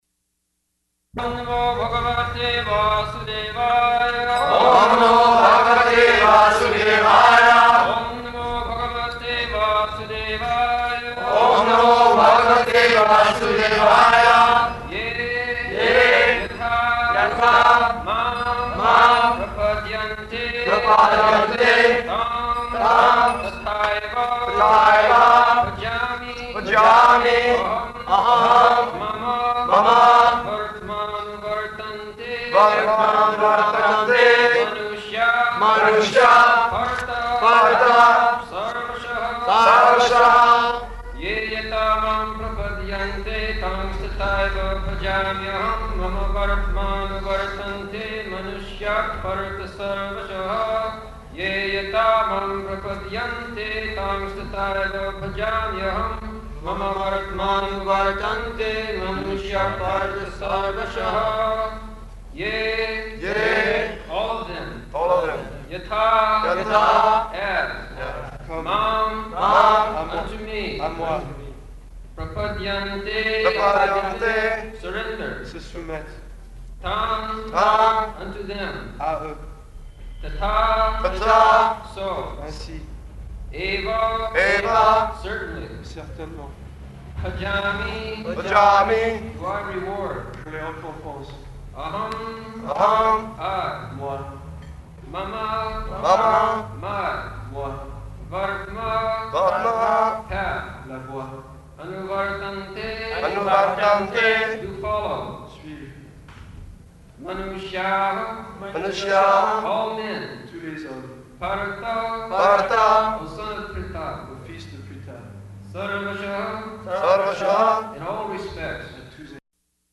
June 1st 1974 Location: Geneva Audio file
[translated into French throughout]
[Prabhupāda and devotees repeat] [leads chanting of verse, etc.]